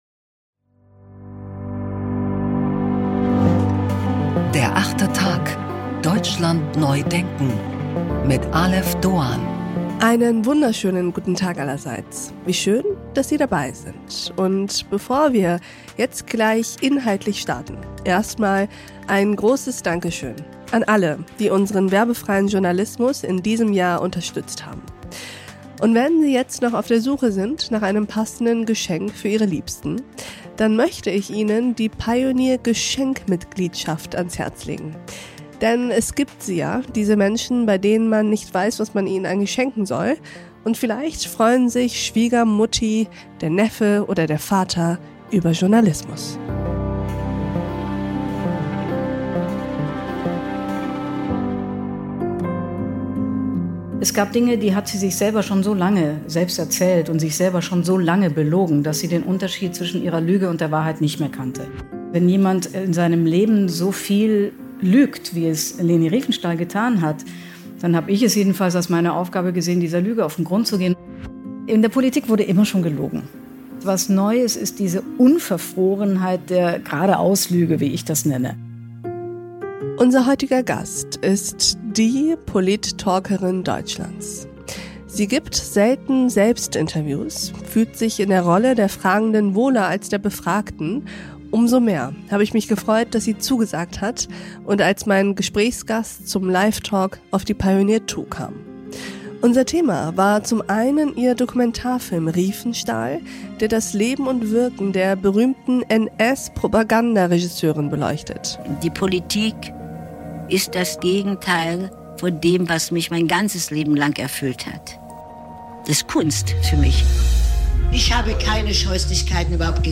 Journalistin Sandra Maischberger über Wahrheiten, Informations-Fatigue und ihren Film Riefenstahl.
Umso mehr haben wir uns gefreut, dass sie zugesagt hat und als Gesprächsgast zum Live-Talk auf die Pioneer Two kam.